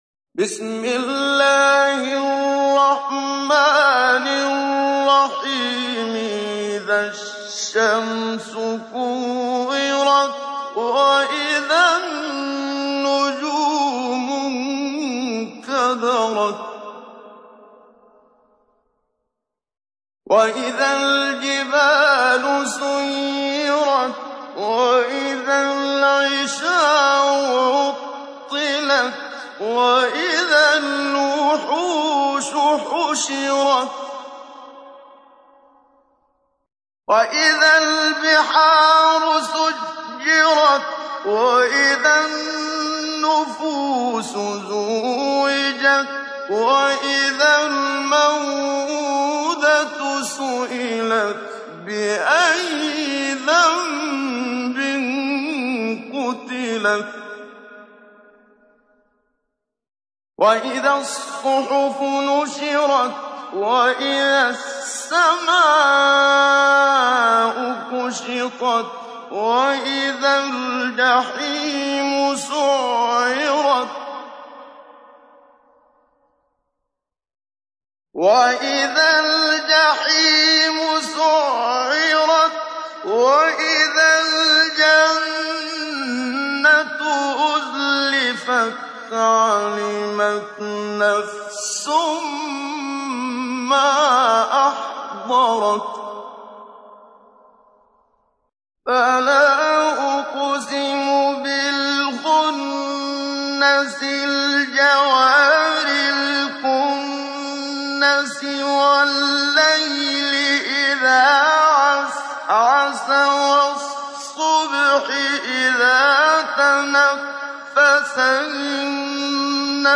تحميل : 81. سورة التكوير / القارئ محمد صديق المنشاوي / القرآن الكريم / موقع يا حسين